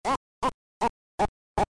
AOL_Ganon_Laugh.mp3